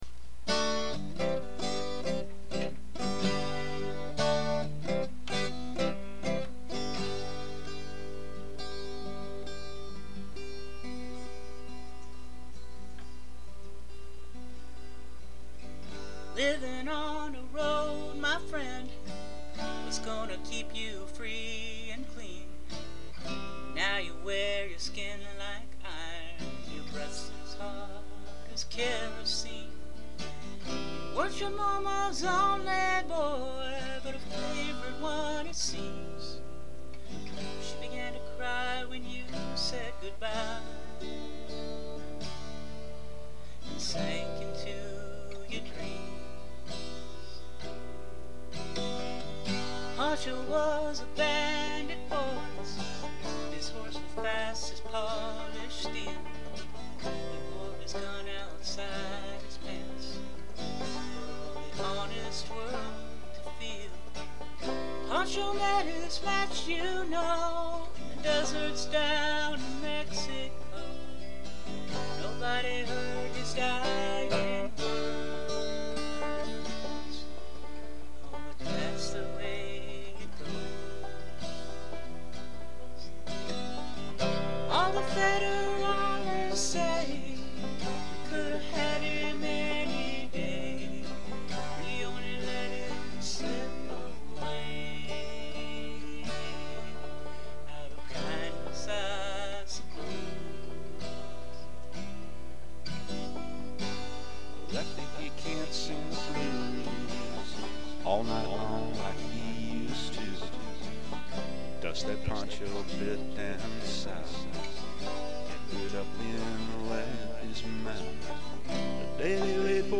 Country
12 String Guitar, Vocals
Banjo, Vocals